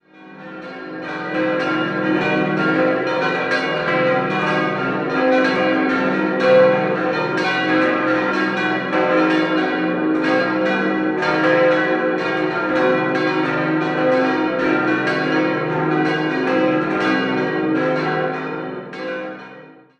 6-stimmiges Geläute: c'-es'-f'-g'-b'-des''
Herz-Jesu-Glocke
Marienglocke
Das Geläute weicht vom ursprünglich geplanten Motiv c'-es'-f'-g'-b'-c'' deutlich ab.